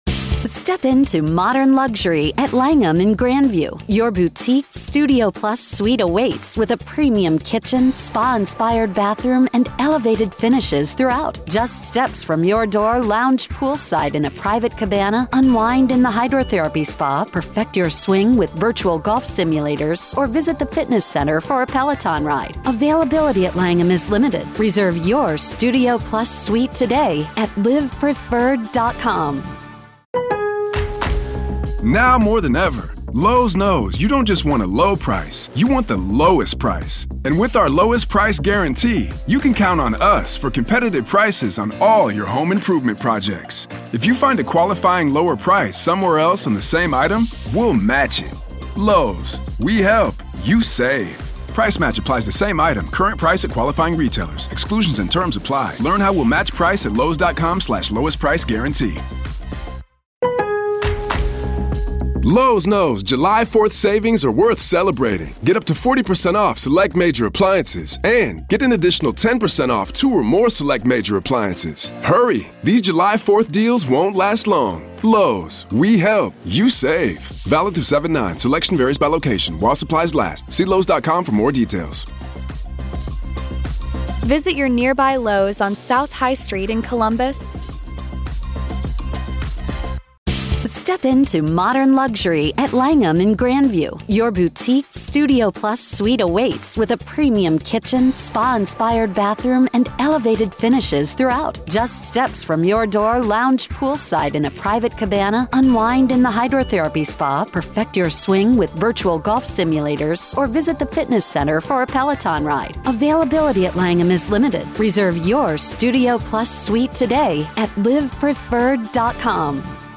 True Crime News & Commentary / What Other Surprises Is Rex Heuermann Hiding?